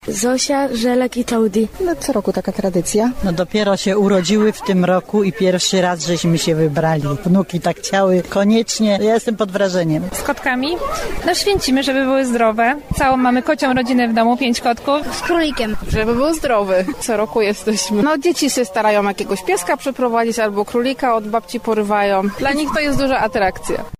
Konie, krowy, psy koty, żółwie i gołębie poświęcili dziś wierni podczas dorocznego obrzędu w Mikstacie w powiecie ostrzeszowskim (na południu Wielkopolski).
- mówili mieszkańcy.